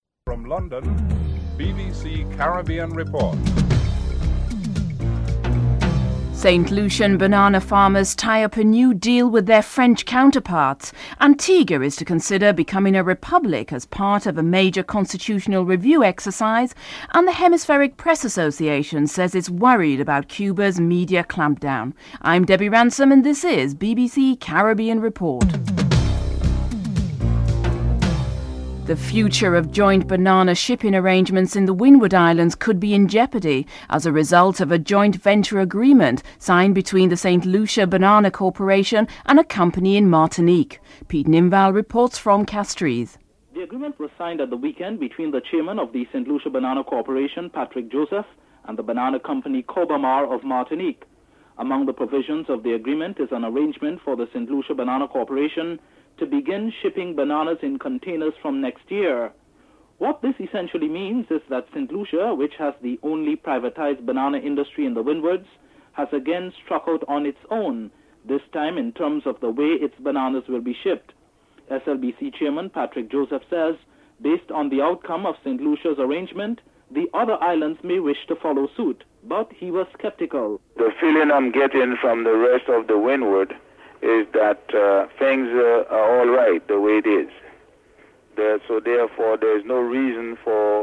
The British Broadcasting Corporation
1. Headlines with anchor